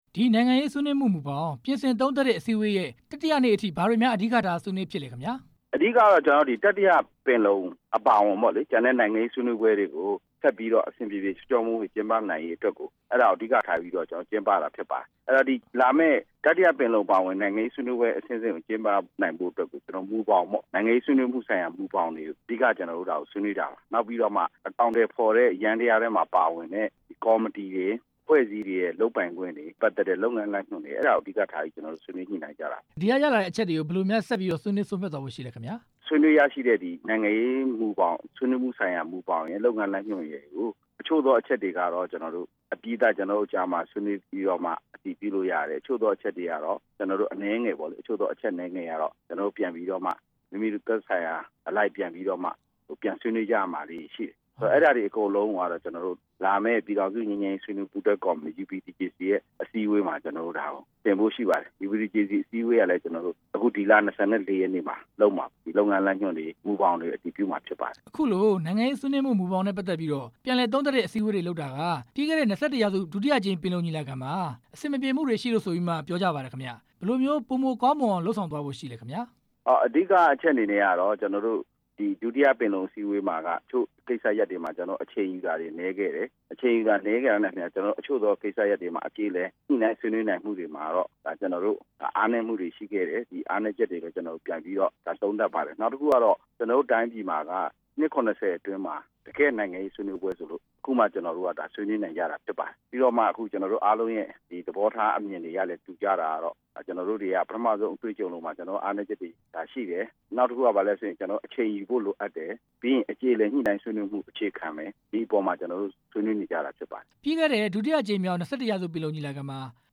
UPDJC အစည်းအဝေး အကြောင်း မေးမြန်းချက်